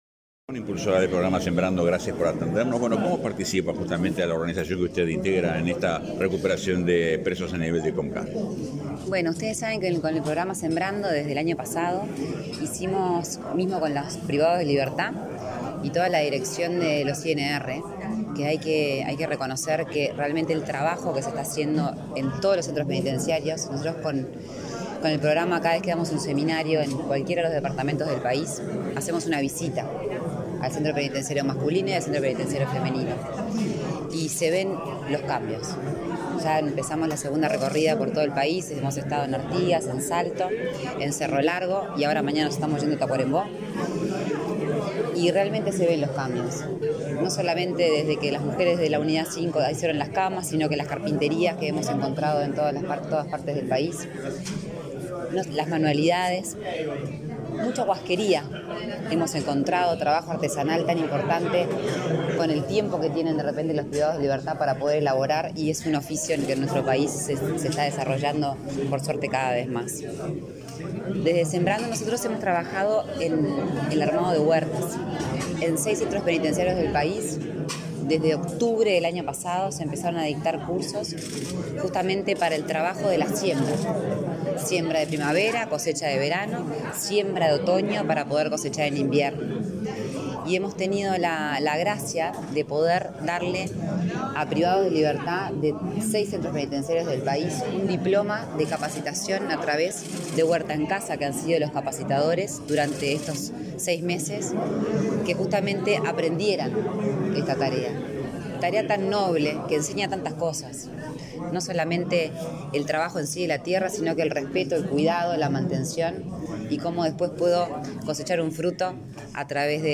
Declaraciones a la prensa de la impulsora del programa Sembrando, Lorena Ponce de León
Tras participar en el primer aniversario del sector de preegreso de la Unidad 4 del Instituto Nacional de Rehabilitación (INR), en Santiago Vázquez,